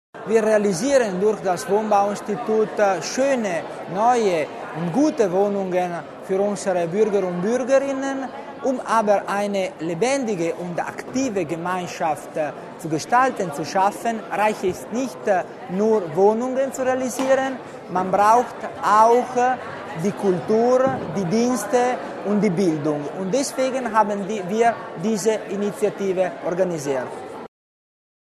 Landesrat Tommasini zur Bedeutung des Projekts